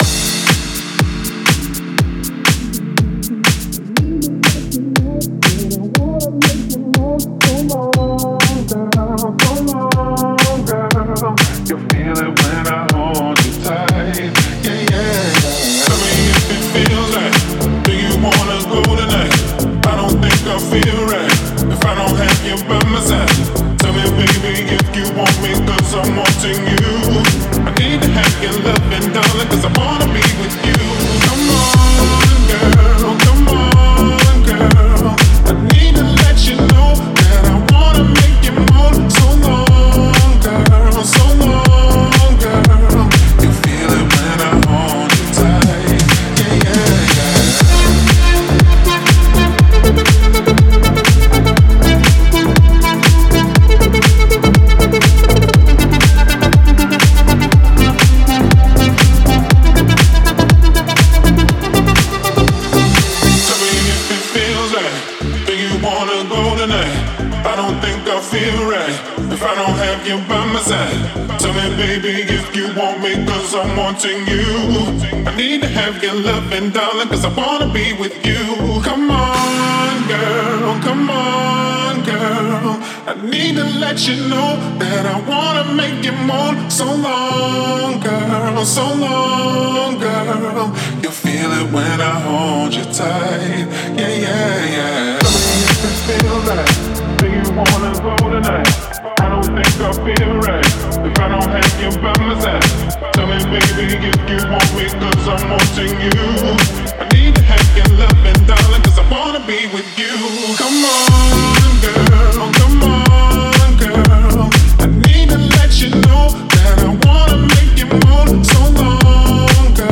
это энергичная и зажигательная песня в жанре поп-рэп